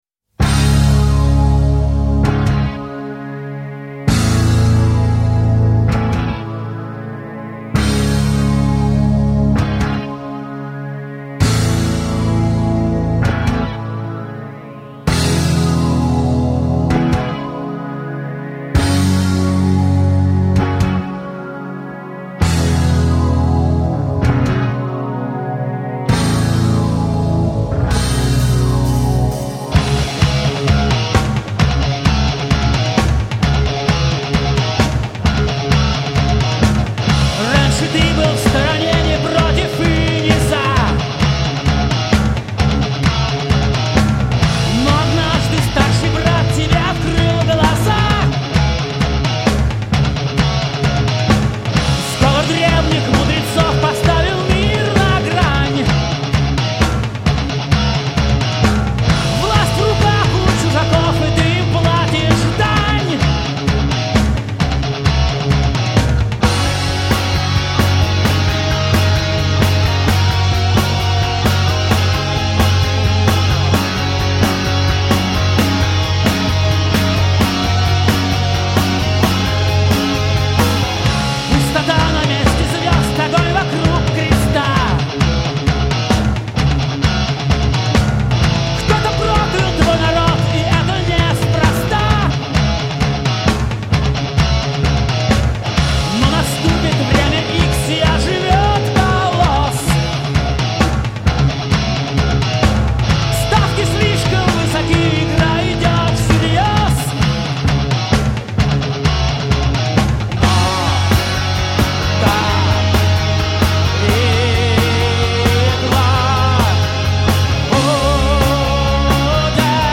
Файл в обменнике2 Myзыкa->Русский рок
Жанр:Рок